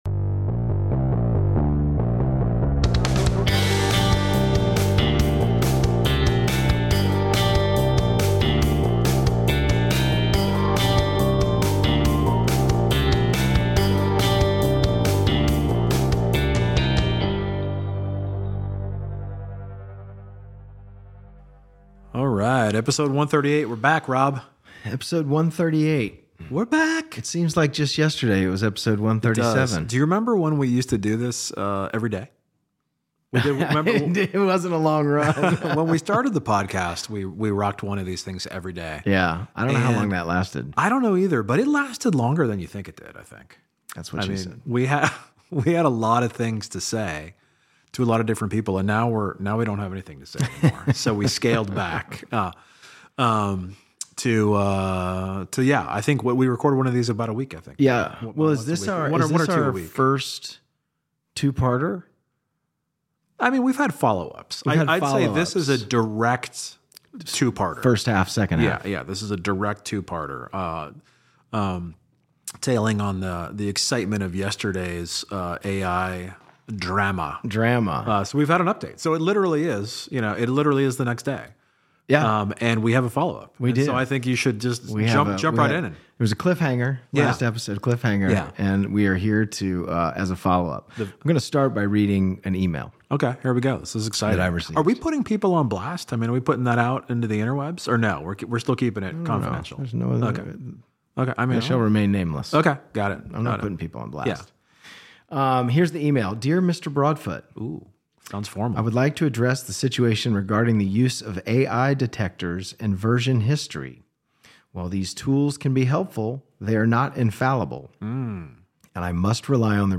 With a mix of personal anecdotes, thoughtful analysis, and a dash of humor, they explore how technology and human judgment intersect in unexpected ways. Tune in for an insightful conversation that questions the reliability of AI and champions the power of human reasoning.